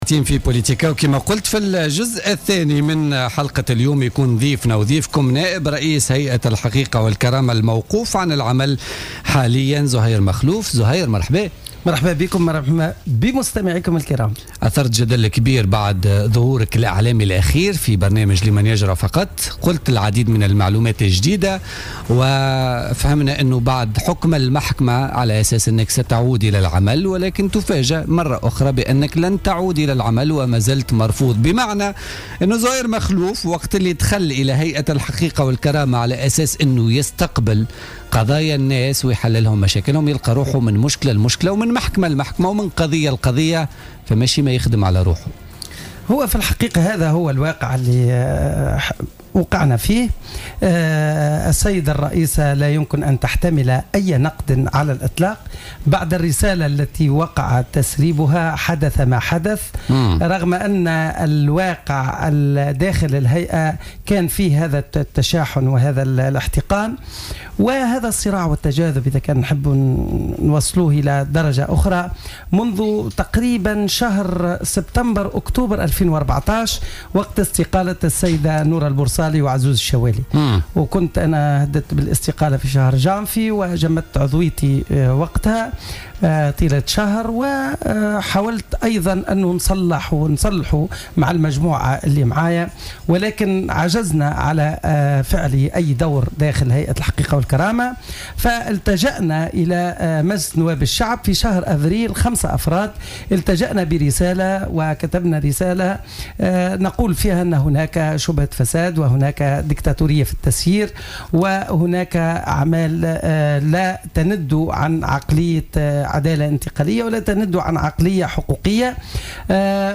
قال عضو هيئة الحقيقة والكرامة، زهير مخلوف، ضيف برنامج "بوليتيكا" اليوم الجمعة إن هناك من استغل الهيئة من أجل الارتزاق.